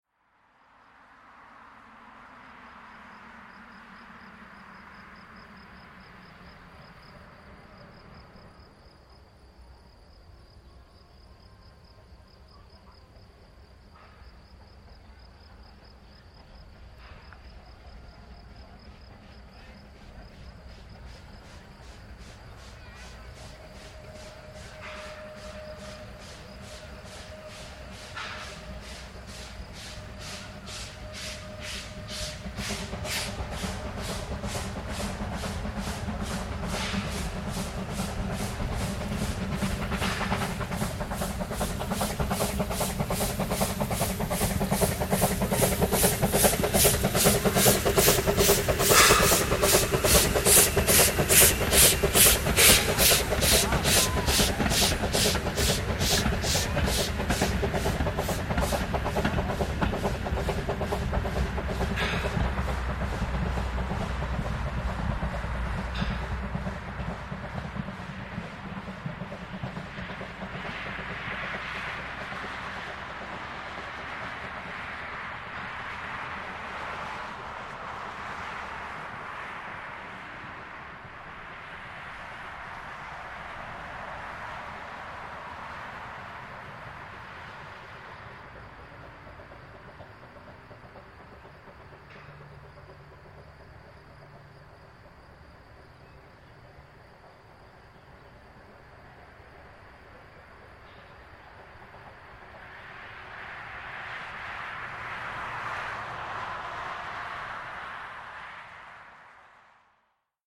Wie immer, ist es aufgrund der extrem nahen und viel befahrenen Landstraße an dem einzigen „Berg“ dieser Bahn ziemlich schwierig, etwas weniger gestörte Soundaufnahmen zustande zu bringen. Die folgenden 3 Szenen sind bei diesem Versuch nun insofern als einigermaßen hinnehmbar zu bezeichnen.
W.N. 12 Tv mit Zug P 2 von Katzenstein zurück nach Neresheim, hinter dem Landstraßen-Bahnübergang unterhalb von Neresheim, um 12:19h am 19.05.2024.   Hier anhören: